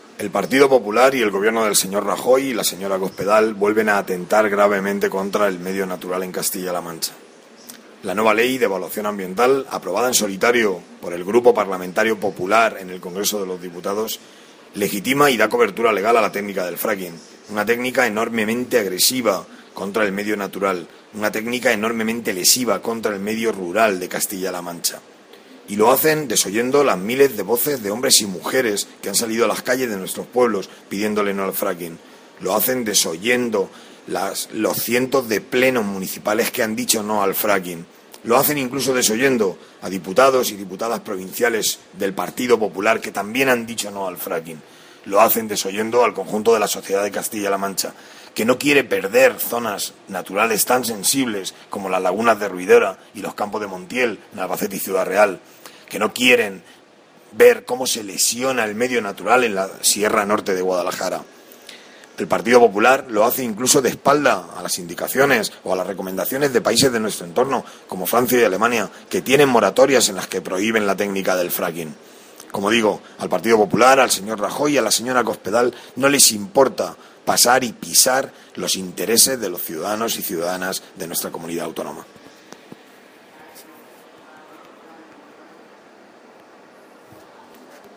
Modesto Belinchón, portavoz de Industria y Energía del Grupo Parlamentario Socialista, considera que esta técnica es “enormemente agresiva con el medio natural y rural de Castilla-La Mancha”, ya que, entre otros peligros, supone la contaminación de los acuíferos y la destrucción medio ambiental de las comarcas donde se lleve a cabo.
Cortes de audio de la rueda de prensa